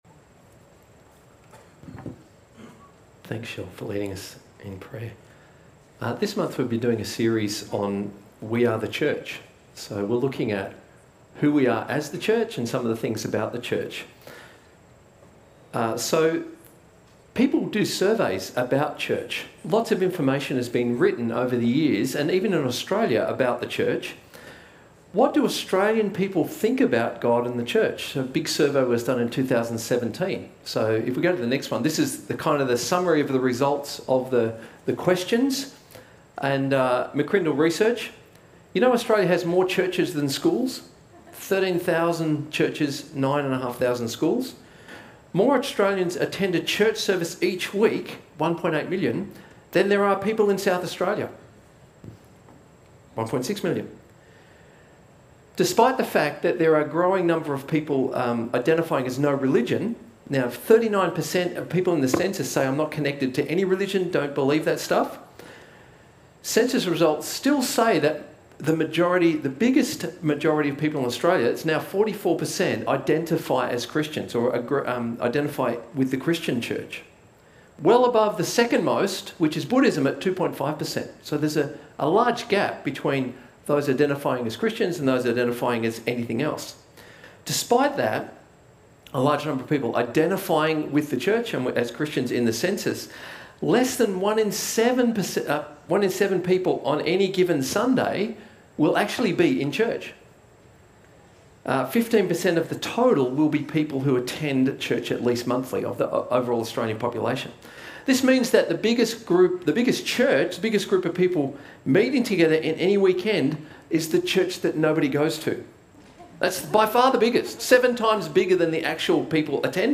A message from the series "We are the Church."